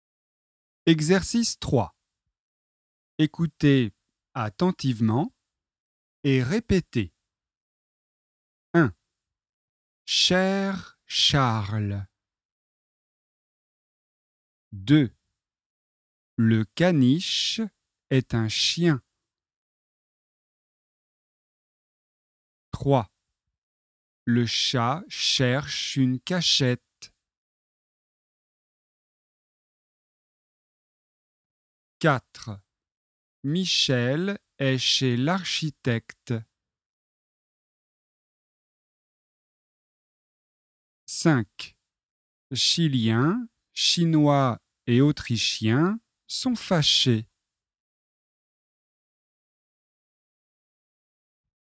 Leçon de phonétique et exercice de prononciation